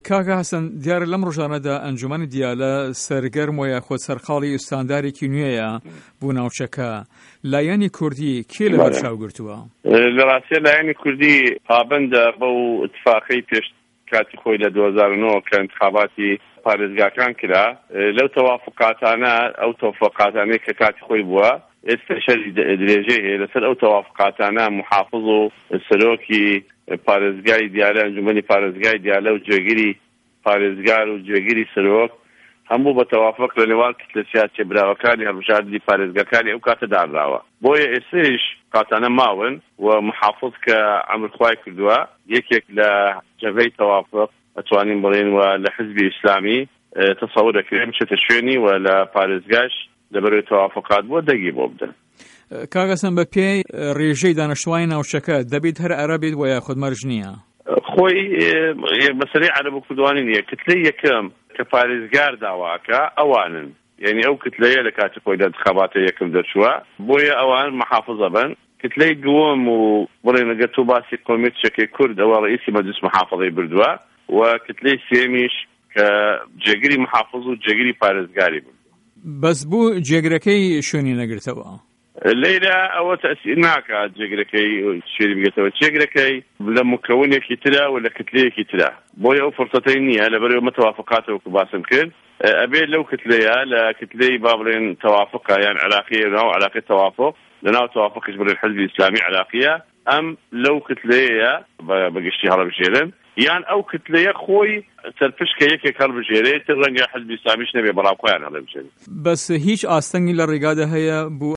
وتو وێژ له‌گه‌ڵ حه‌سه‌ن جیهاد